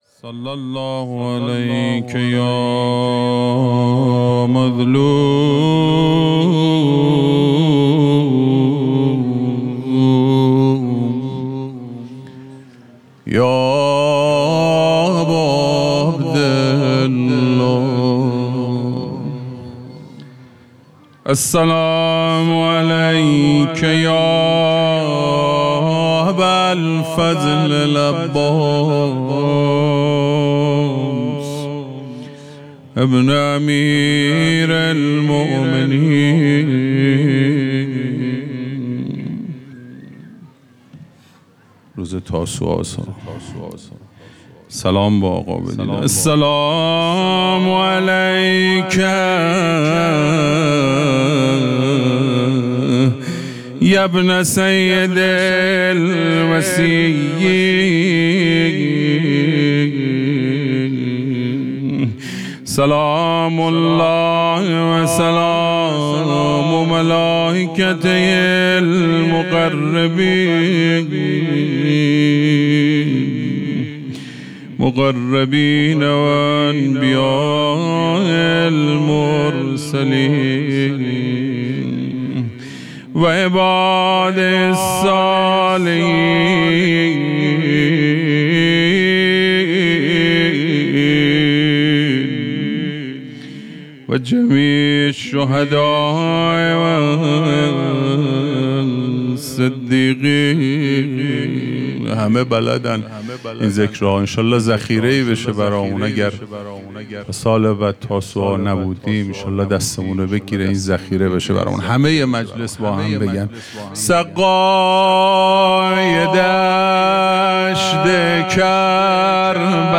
خیمه گاه - هیئت محبان الحسین علیه السلام مسگرآباد - روضه پایانی